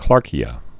(klärkē-ə)